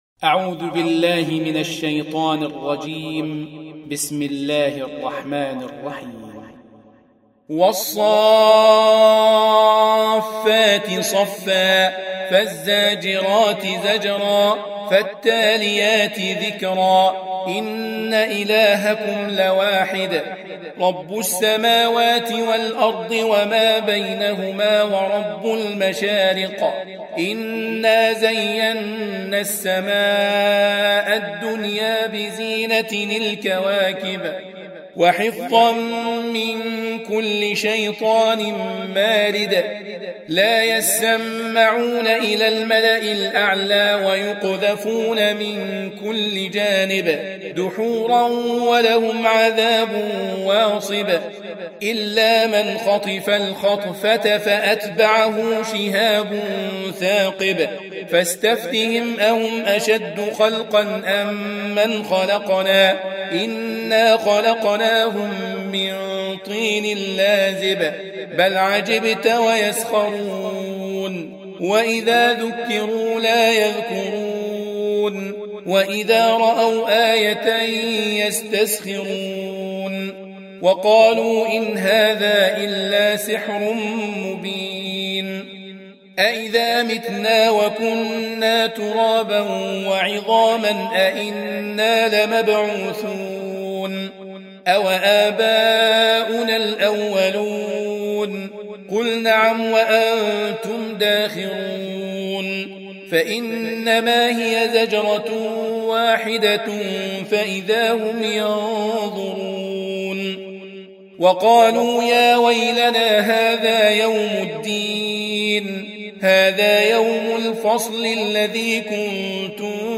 Surah Sequence تتابع السورة Download Surah حمّل السورة Reciting Murattalah Audio for 37. Surah As-S�ff�t سورة الصافات N.B *Surah Includes Al-Basmalah Reciters Sequents تتابع التلاوات Reciters Repeats تكرار التلاوات